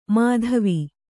♪ mādhavi